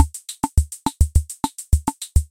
Tag: 循环 节奏 Drumloop 节拍 鼓机节奏口技